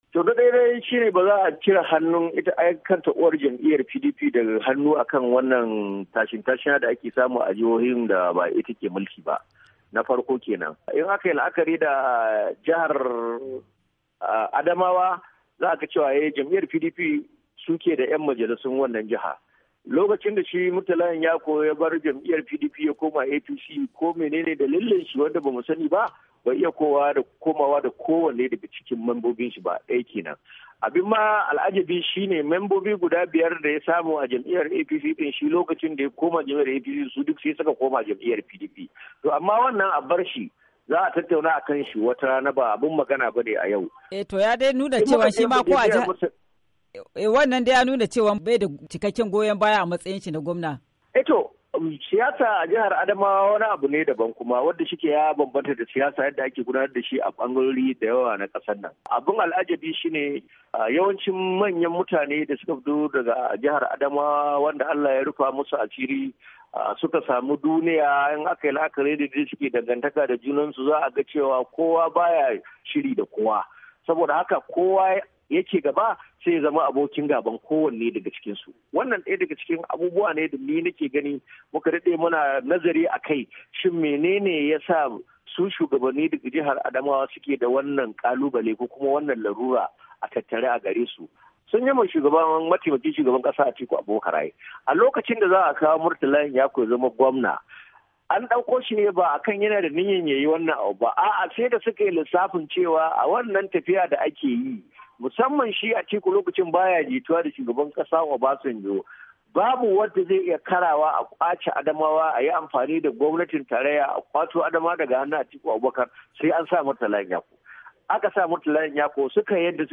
A firar da yayi